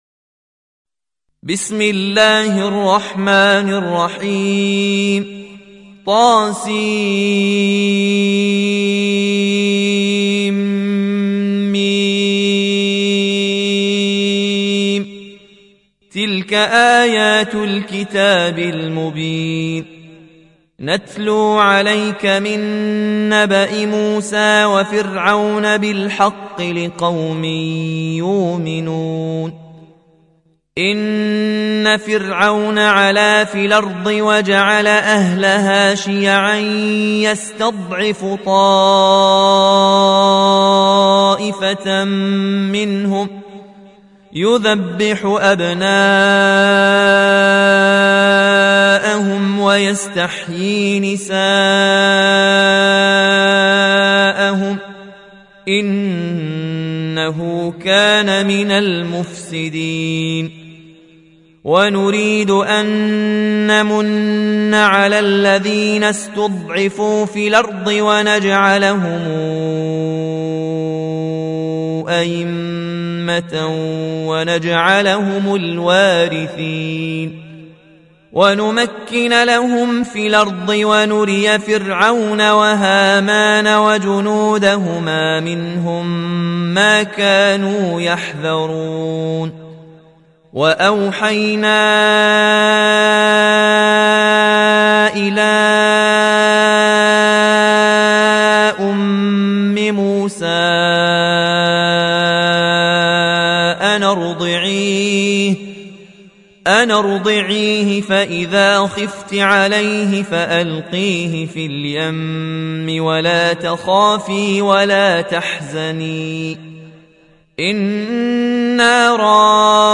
(روایت ورش)